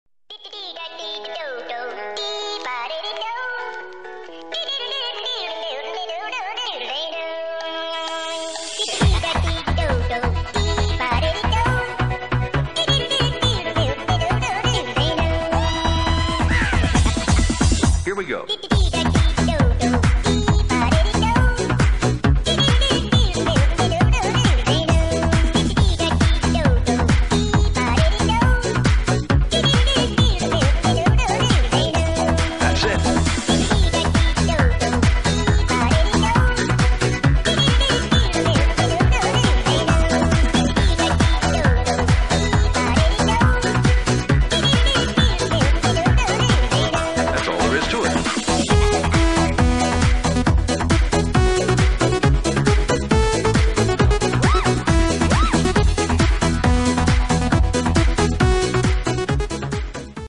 lol sound effects free download